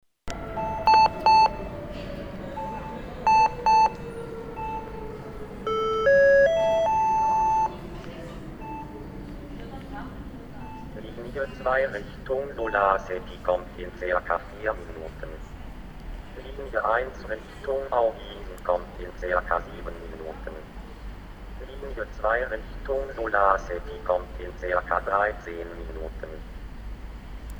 DISA – Digitale Sprachausgabe an den Haltestellen der Linz AG Linien.
Hörbeispiel DISA mit Ansage der Ankunftszeiten: